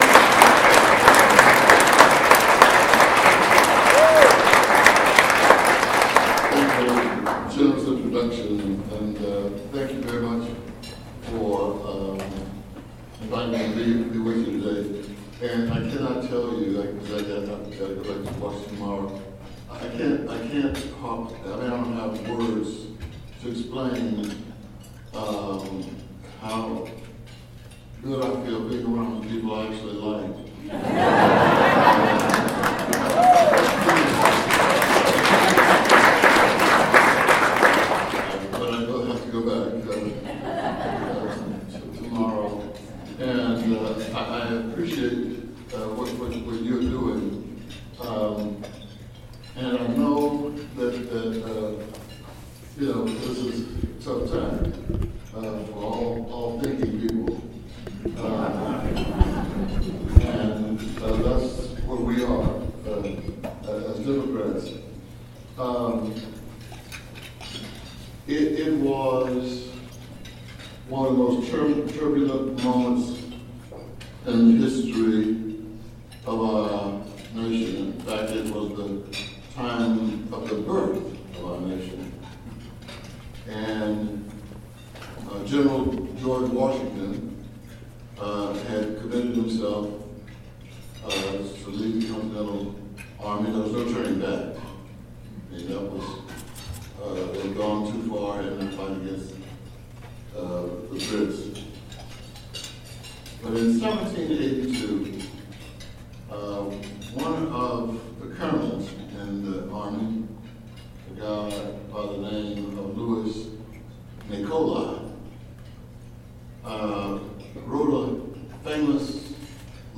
Yesterday in Warrensburg the Johnson County Democratic Central Committee hosted its 19th annual James C. Kirkpatrick celebration. Representative Emanuel Cleaver (D) was the keynote speaker.